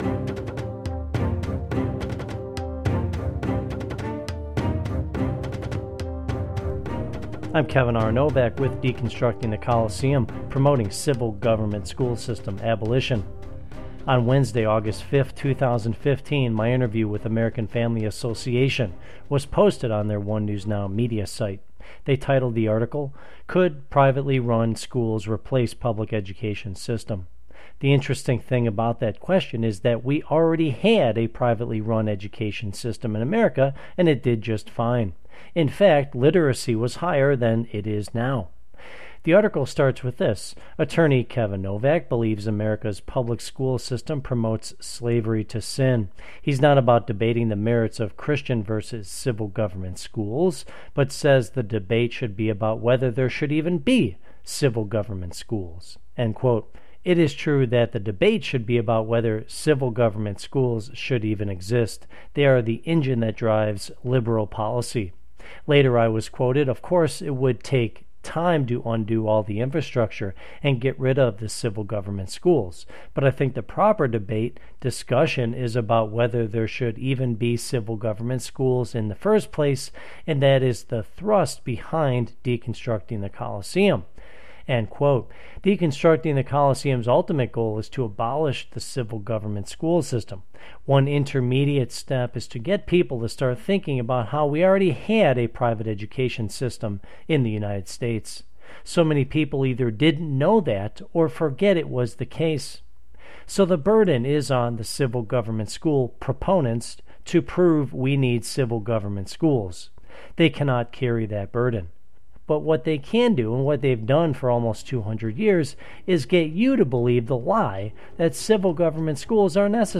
Briefs: My interview with One News Now